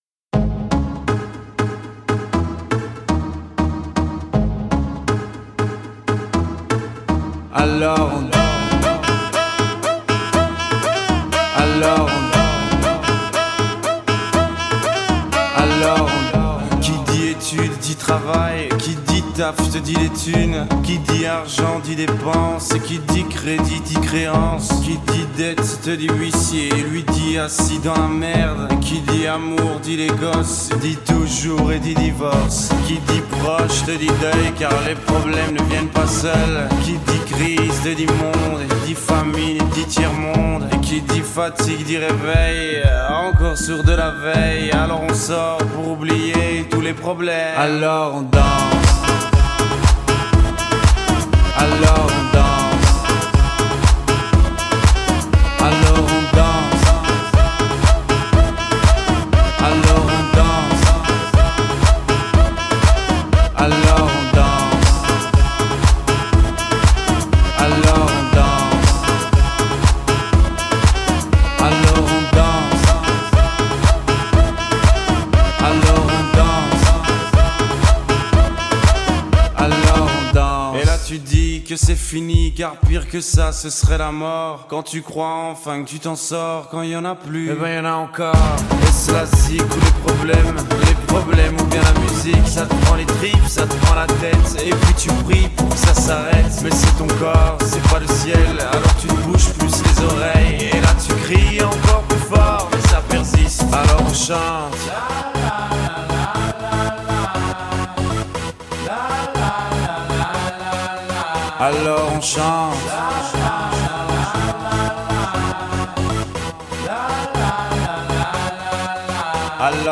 hip hop/electro
Pop